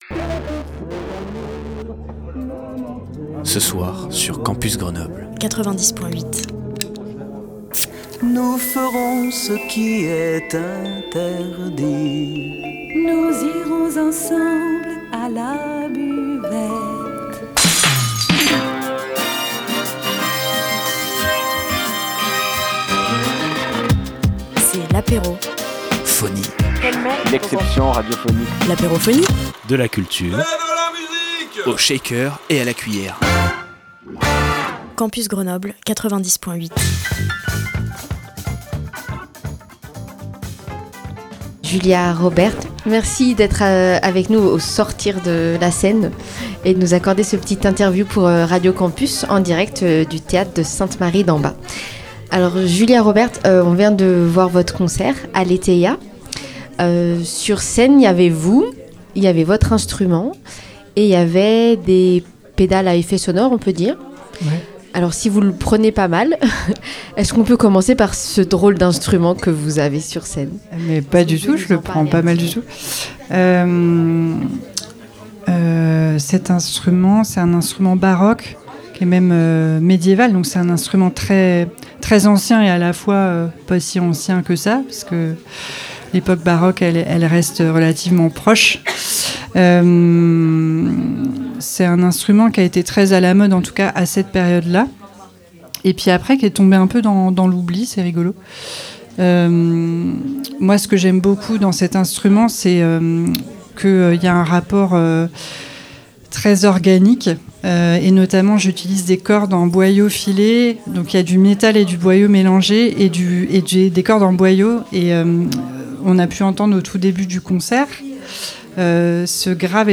A l’occasion des Détours de Babel, Radio Campus Grenoble s’est aménagé un studio au théâtre Sainte-Marie-d’en Bas.